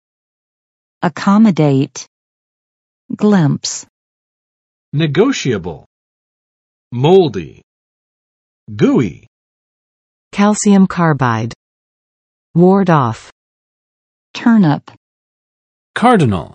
[əˋkɑmə͵det] v. 给……方便